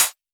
RDM_Copicat_SY1-HfHat.wav